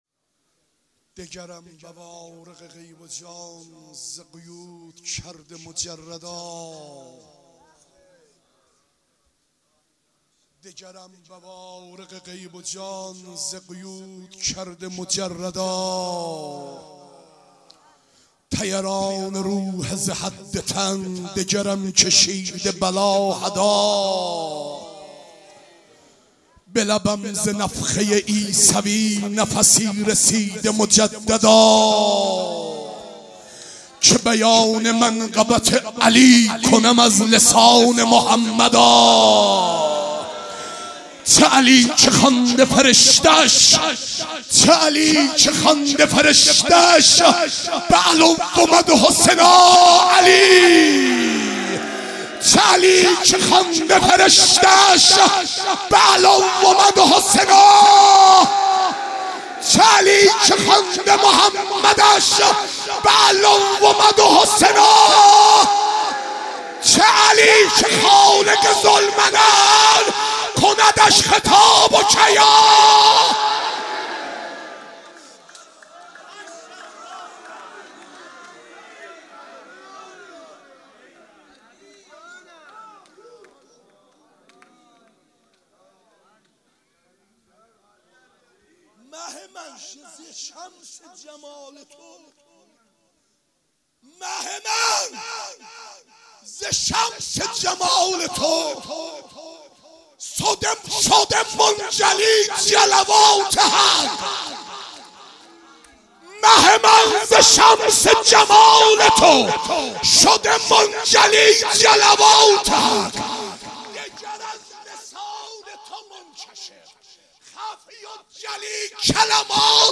مشهد الرضا - مدح و رجز - 11 - 1395